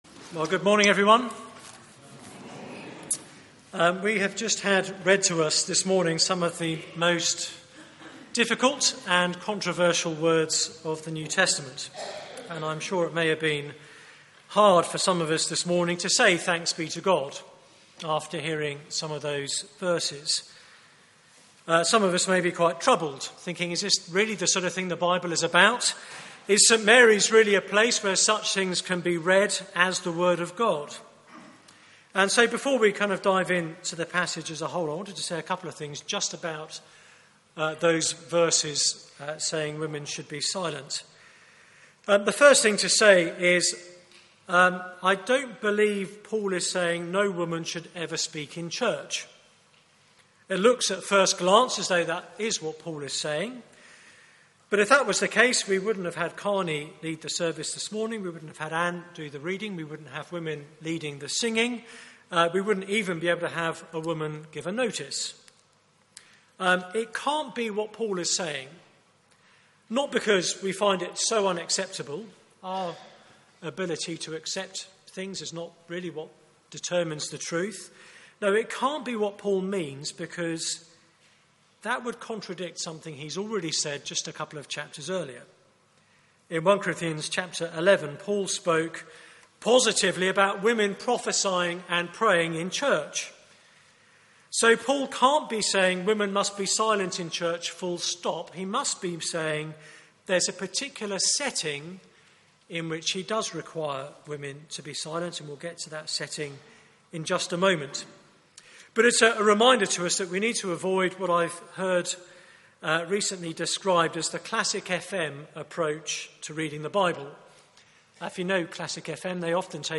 Media for 9:15am Service on Sun 22nd Nov 2015
Series: A Church with Issues Theme: Church done properly Sermon